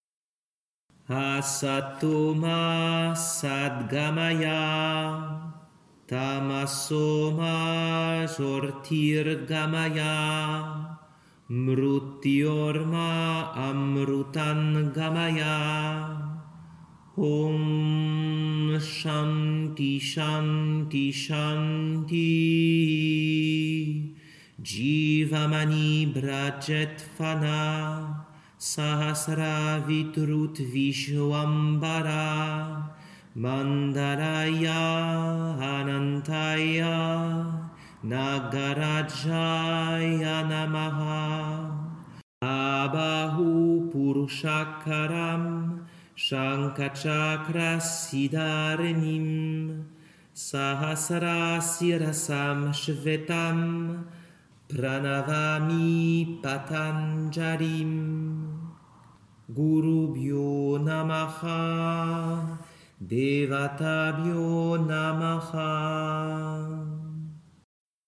Mantras
Mantra d’ouverture